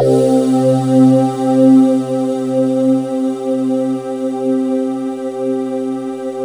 Index of /90_sSampleCDs/USB Soundscan vol.28 - Choir Acoustic & Synth [AKAI] 1CD/Partition D/22-RESOVOXAR